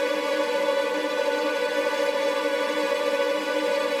GS_TremString-Bmin9.wav